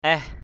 /ɛh/